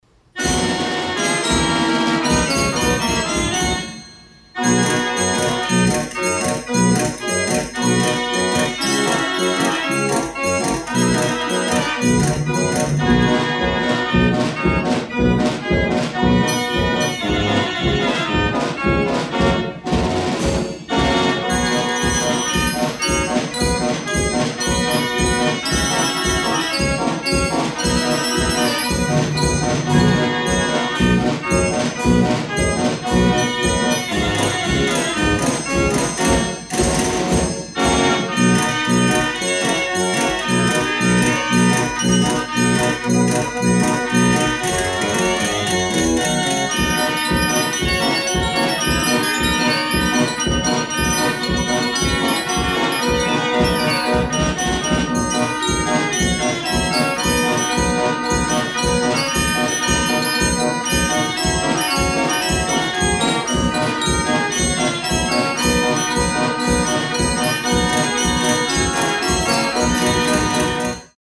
Carousel Fair Organ
her music is very exhilarating